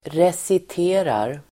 Ladda ner uttalet
recitera verb, recite Grammatikkommentar: A & x Uttal: [resit'e:rar] Böjningar: reciterade, reciterat, recitera, reciterar Synonymer: läsa upp Definition: läsa upp (skönlitteratur) Avledningar: recitation (recitation)